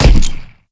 2D-Platformer/Assets/Sounds/Weapons/glauncher2.ogg at master
glauncher2.ogg